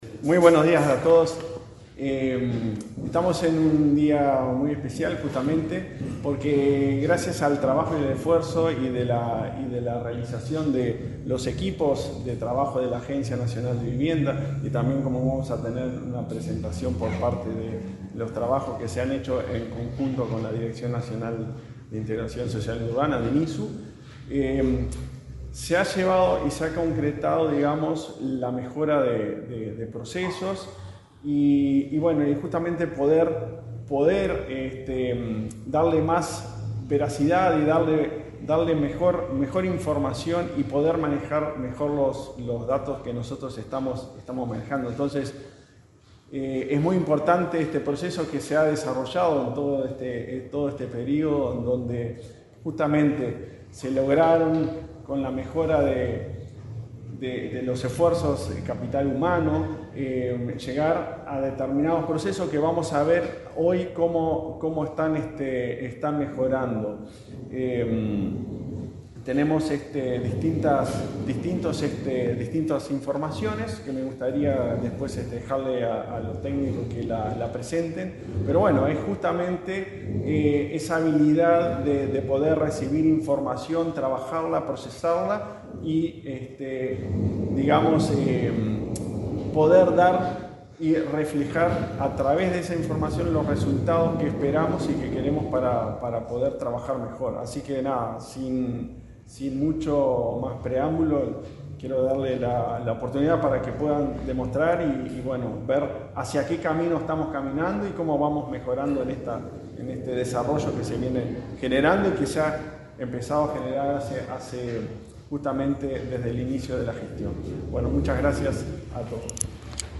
Palabras del presidente de la ANV, Klaus Mill
Este lunes 16, el presidente de la Agencia Nacional de Vivienda (ANV), Klaus Mill, participó en la presentación del análisis de datos de dos programas